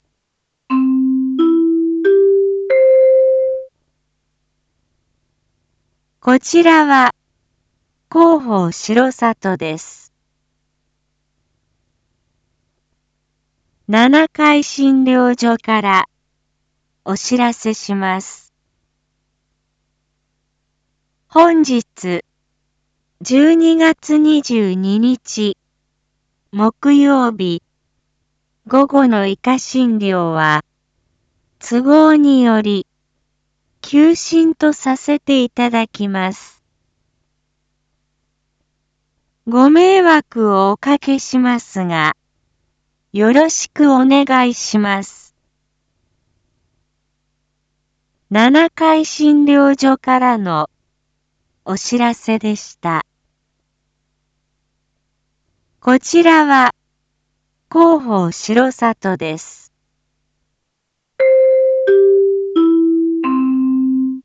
一般放送情報
Back Home 一般放送情報 音声放送 再生 一般放送情報 登録日時：2022-12-22 07:01:05 タイトル：R4.12.22 7時放送分 インフォメーション：こちらは広報しろさとです。